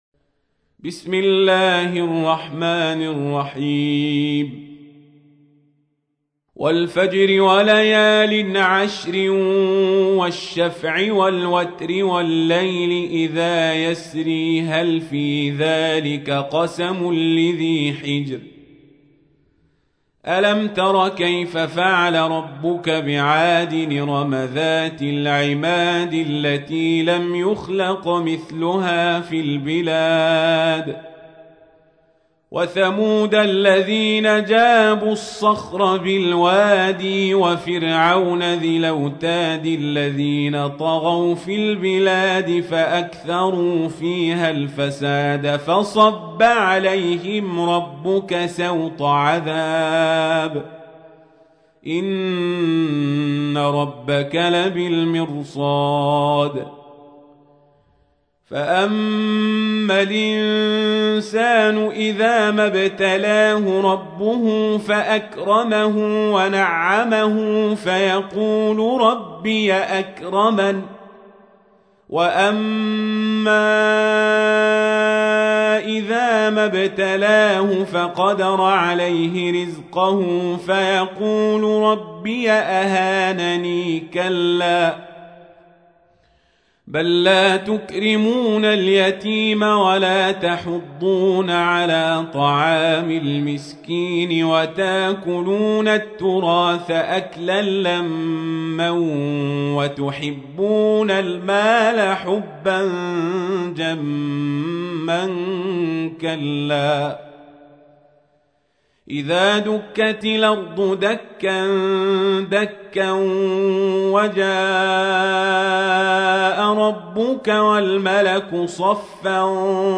تحميل : 89. سورة الفجر / القارئ القزابري / القرآن الكريم / موقع يا حسين